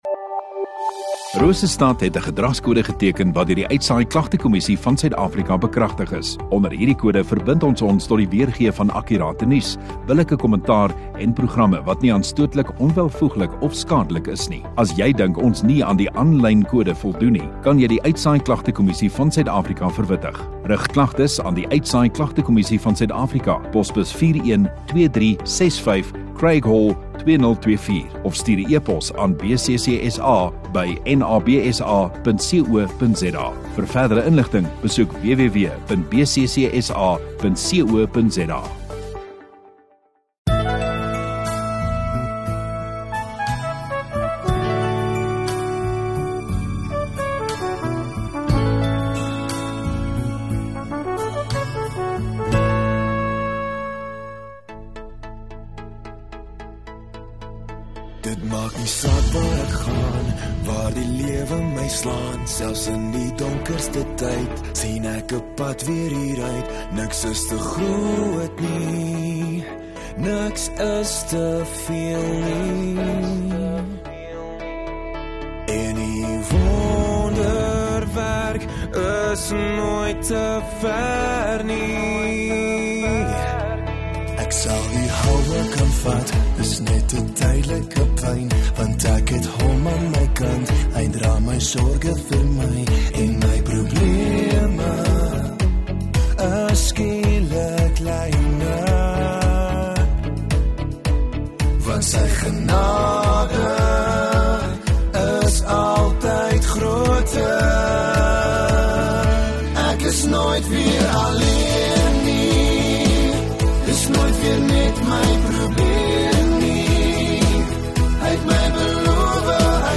31 May Saterdag Oggenddiens